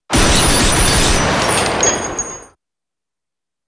40-gun-shots-and-shells-impact.m4a